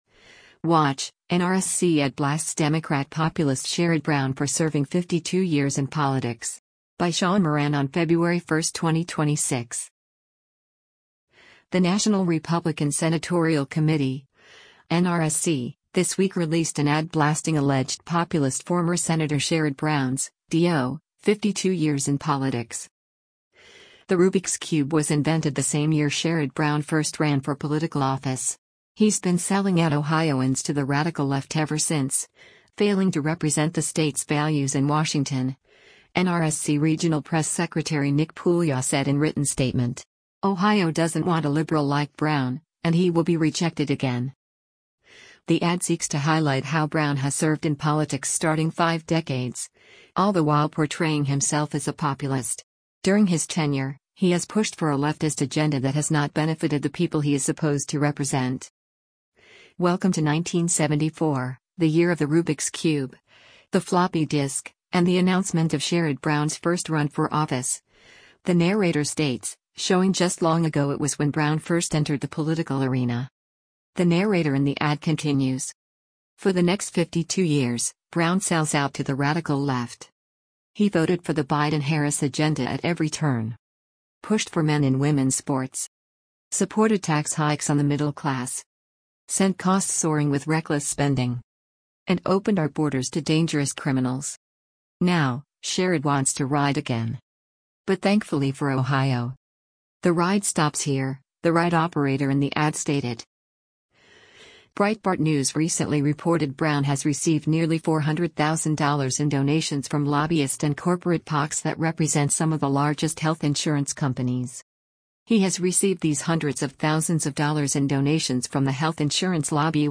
The narrator in the ad continues:
“The ride stops here,” the ride operator in the ad stated.